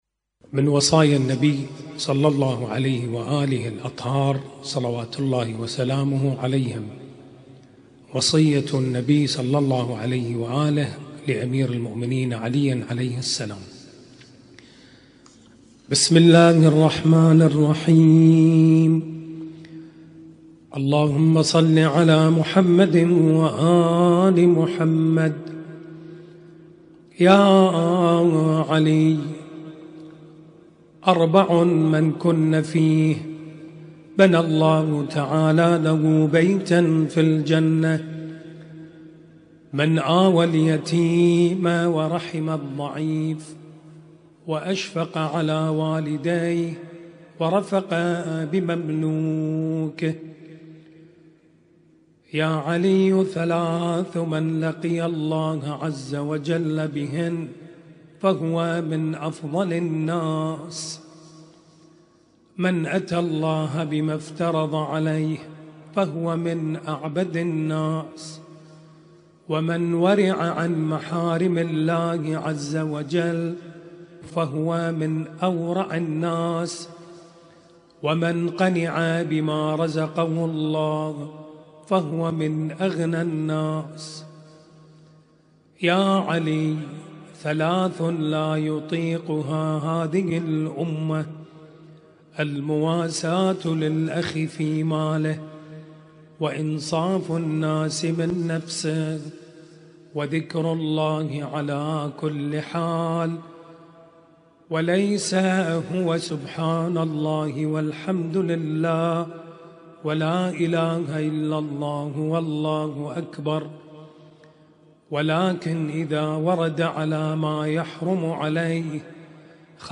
ليلة 12 محرم